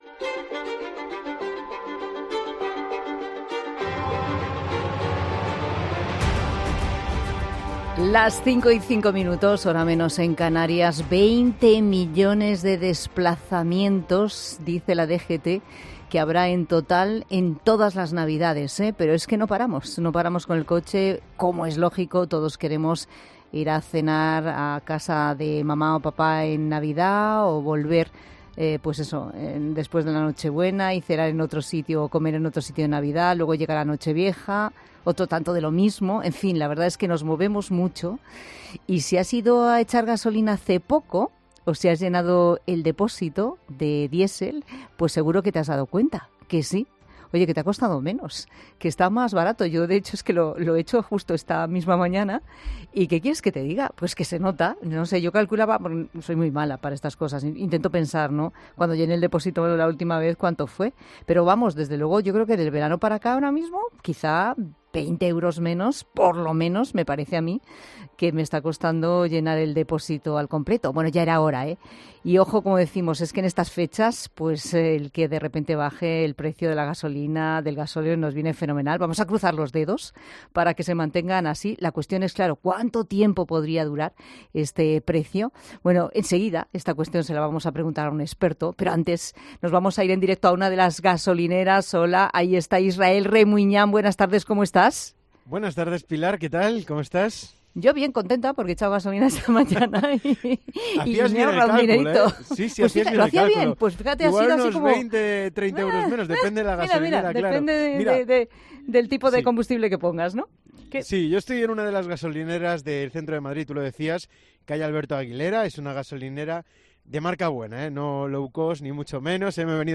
se ha trasladado hasta una gasolinera del centro de Madrid para contarnos cuánto cuesta repostar y llenar un depósito. Desde septiembre la gasolina no ha parado de bajar y se ha situado en mínimos en plena operación navideña.